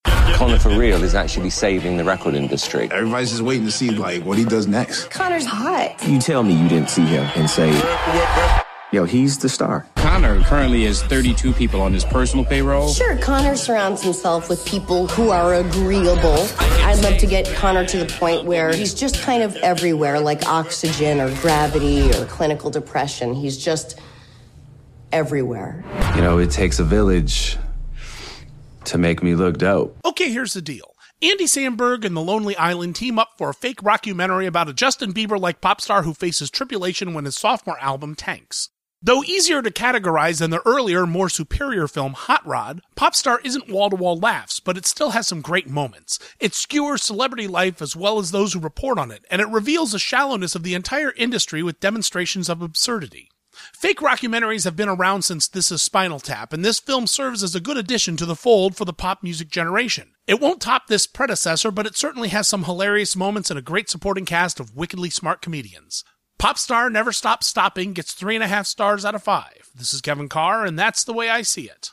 ‘Popstar: Never Stop Never Stopping’ Radio Review